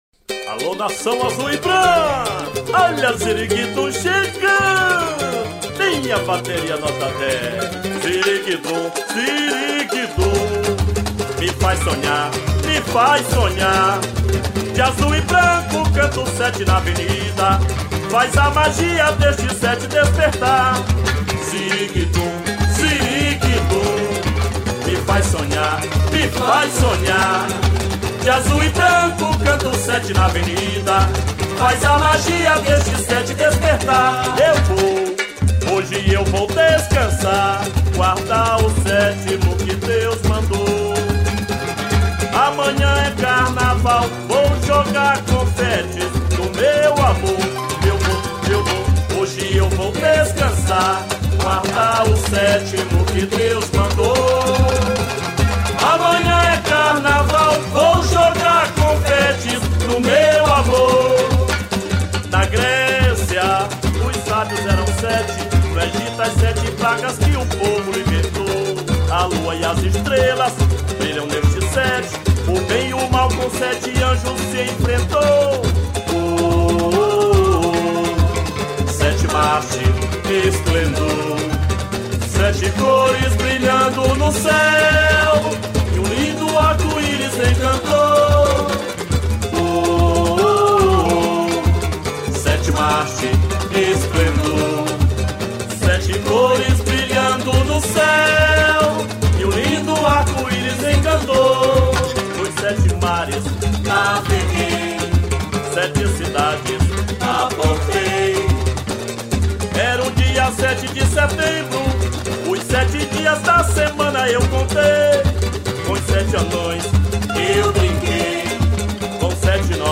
386   05:05:00   Faixa:     Samba Enredo